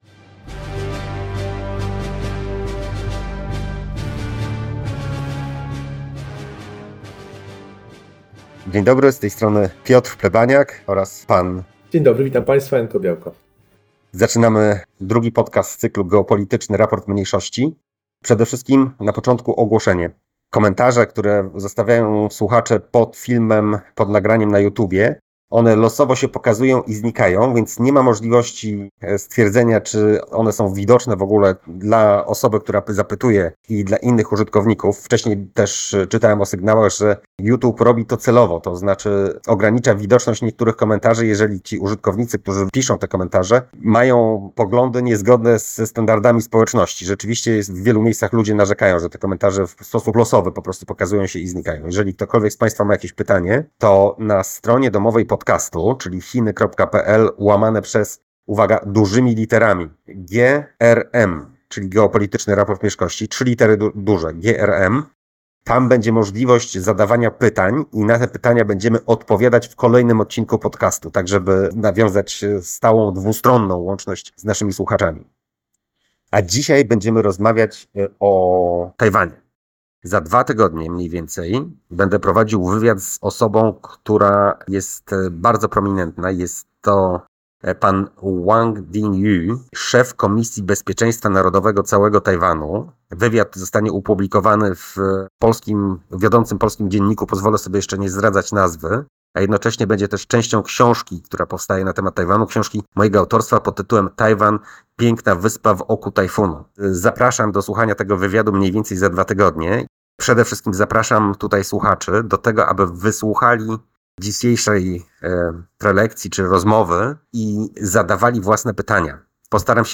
Temat rozmowy: Rozmawiamy o uwikłaniach stron w kontekście inwazji na Tajwan.